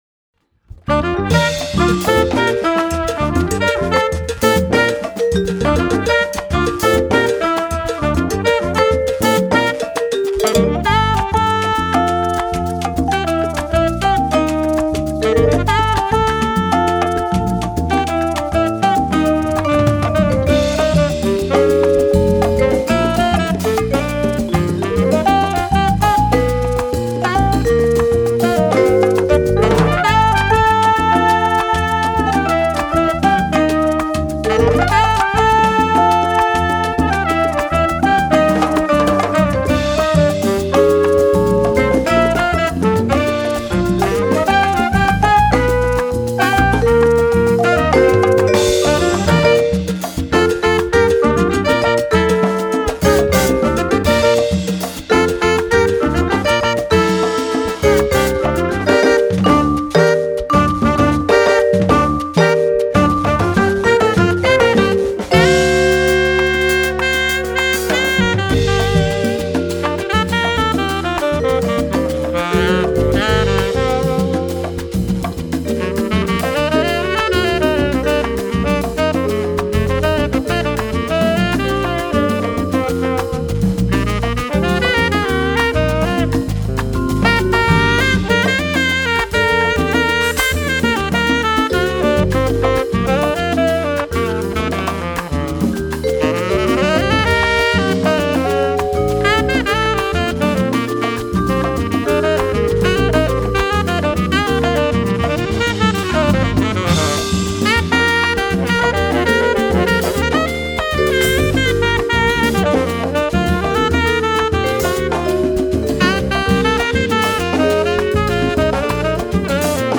With spirited soulful swing, vibraphonist and composer